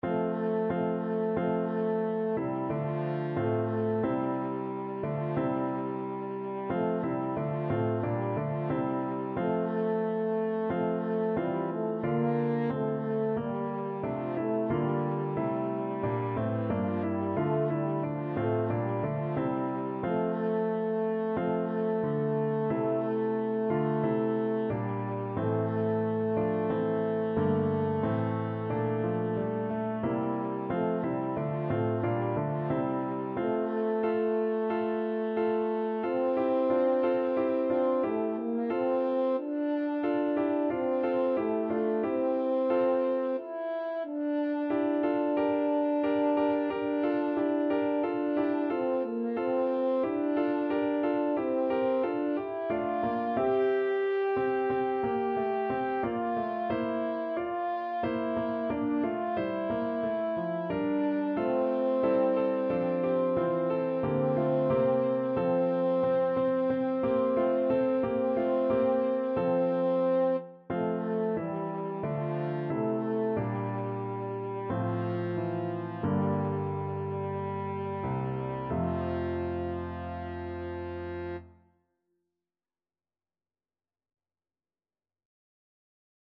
4/4 (View more 4/4 Music)
Classical (View more Classical French Horn Music)